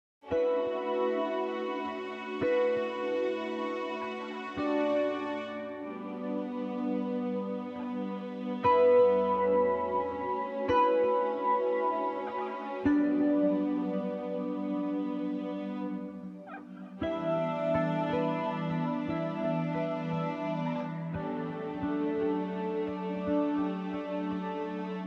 Suprano Sax and Tenor Sax
Guitar; virtual instruments; Drums, Bass, Keys